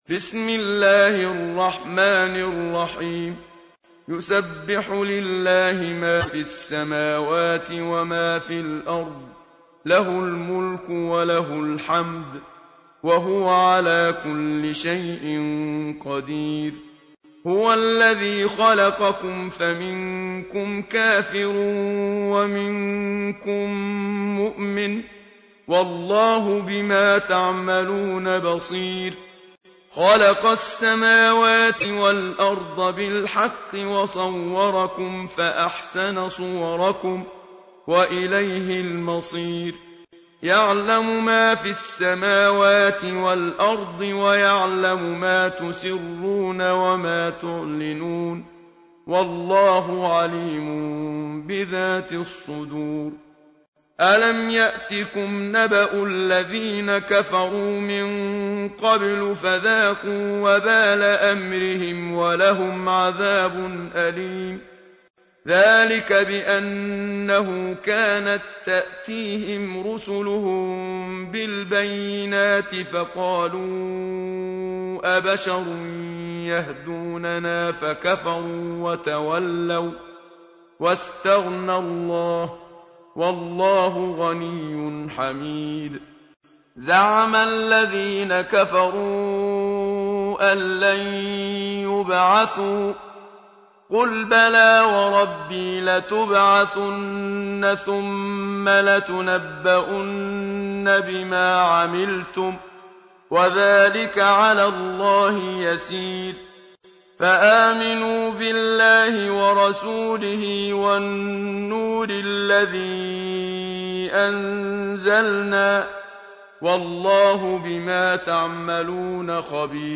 سوره‌ای که خواندنش از مرگ ناگهانی نجات‌تان می‌دهد +متن وترجمه+ترتیل استاد منشاوی